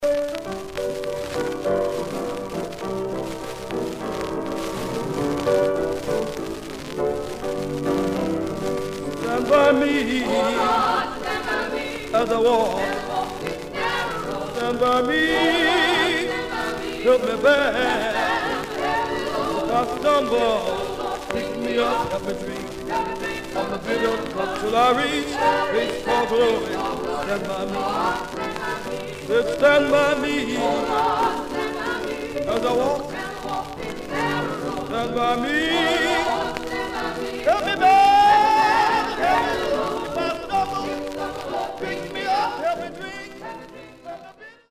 Gospel
Mono